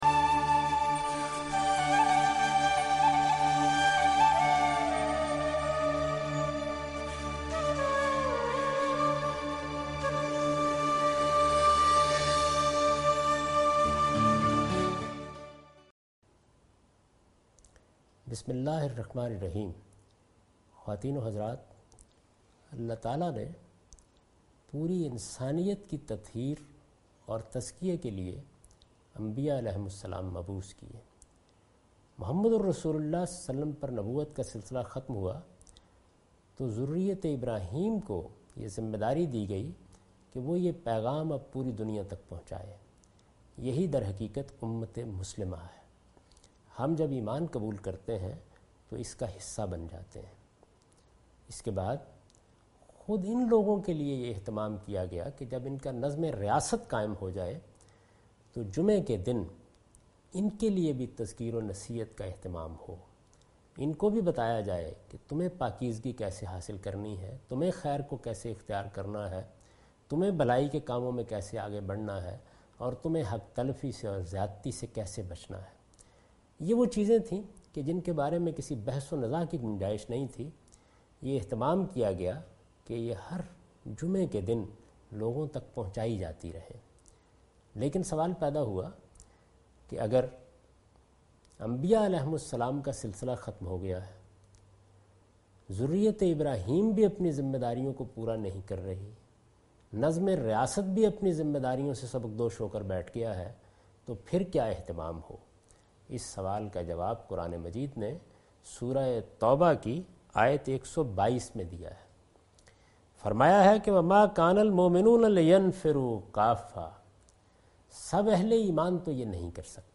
This series contains the lecture of Javed Ahmed Ghamidi delivered in Ramzan.